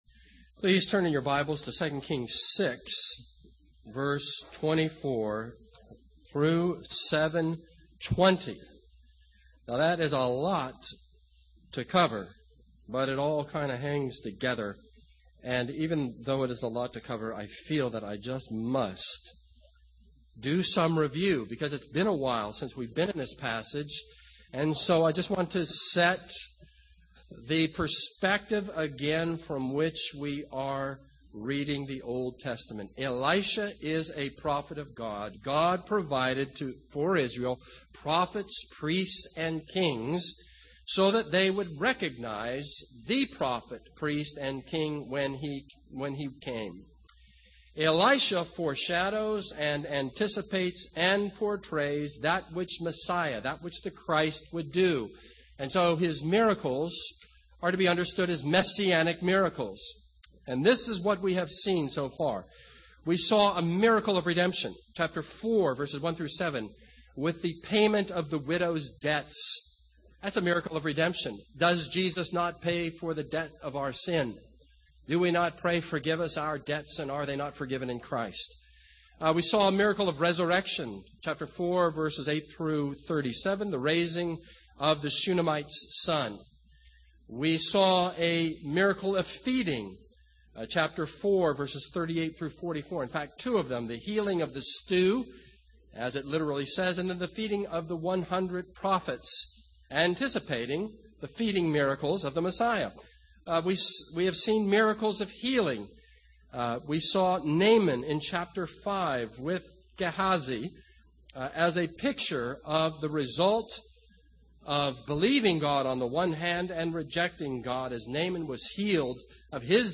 This sermon is based on 2 Kings 6:24-33 and 2 Kings 7:1-20.